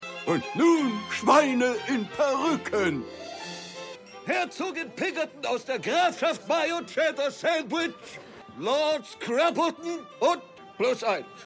Synchronstudio: Iyuno Germany GmbH
DieMuppetShow2026__Ansager_Nomenklator.mp3